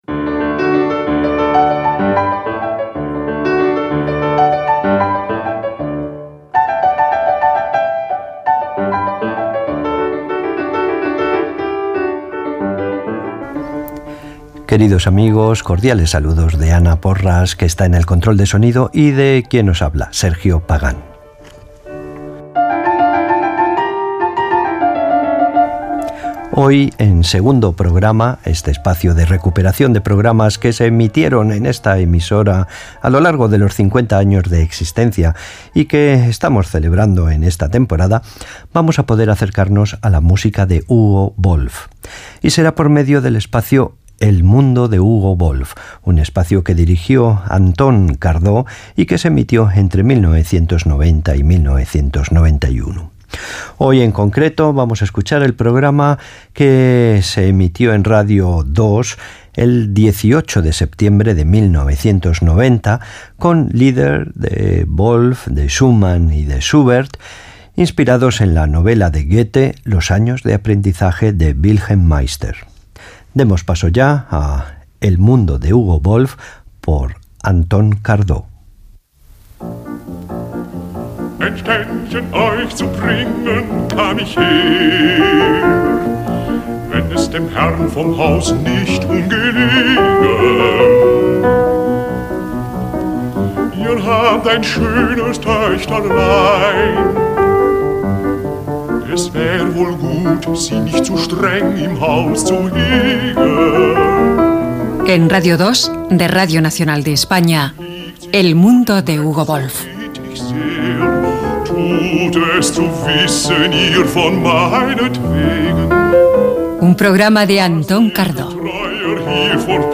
Musical
FM